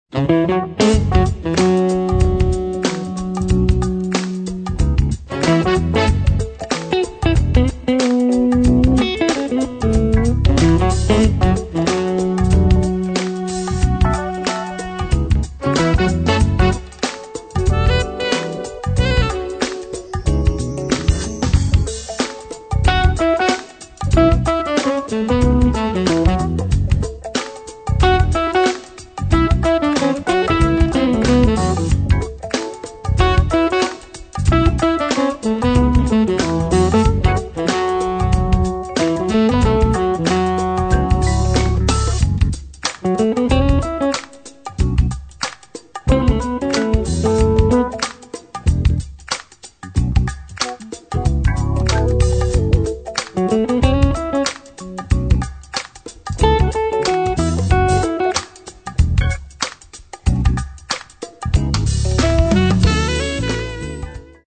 GŁADKI JAZZ